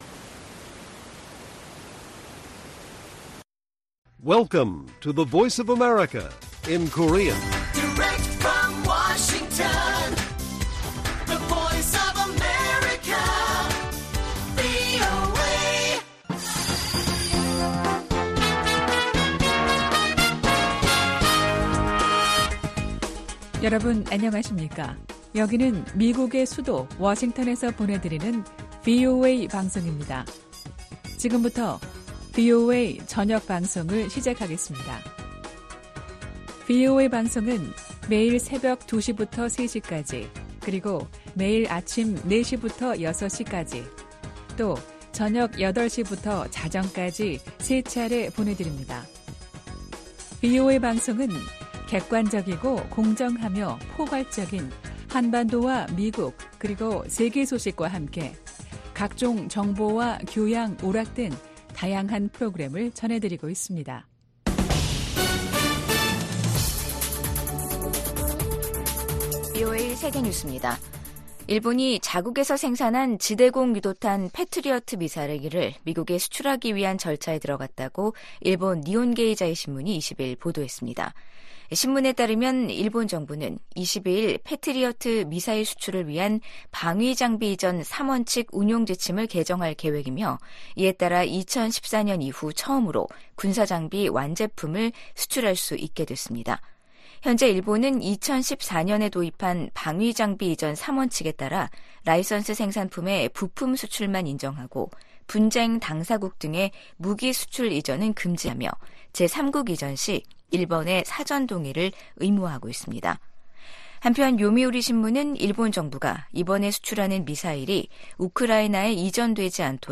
VOA 한국어 간판 뉴스 프로그램 '뉴스 투데이', 2023년 12월 20일 1부 방송입니다. 유엔 안보리가 북한의 대륙간탄도미사일(ICBM) 발사에 대응한 긴급회의를 개최했지만 구체적 대응 조치에 합의하지 못했습니다. 유럽연합(EU)은 북한의 연이은 탄도미사일 발사를 국제 평화에 대한 위협으로 규정하며 강력히 규탄했습니다.